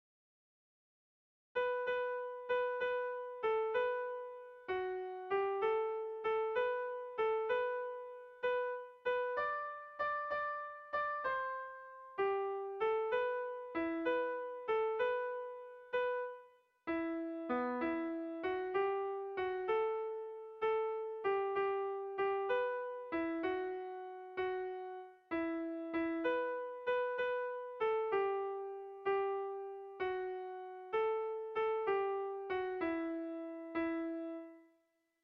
Erlijiozkoa
Zortziko txikia (hg) / Lau puntuko txikia (ip)
ABDE